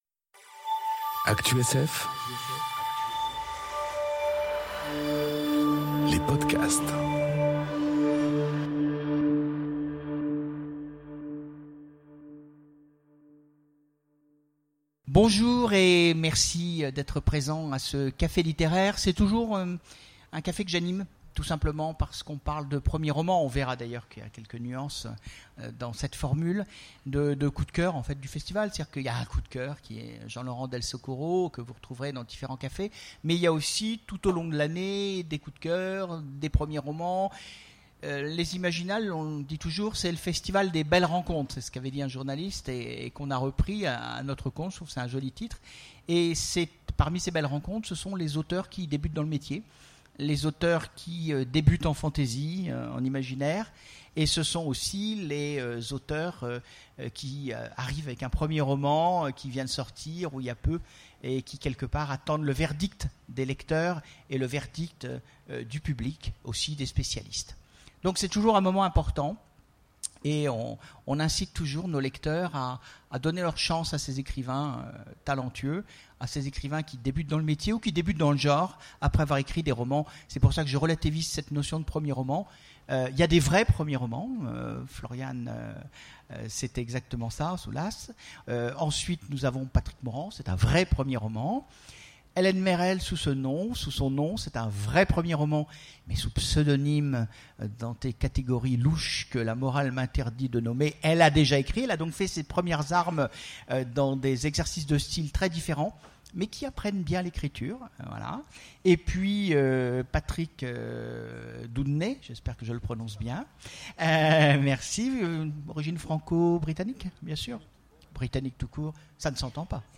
Conférence Premiers romans... Nouveaux talents ! enregistrée aux Imaginales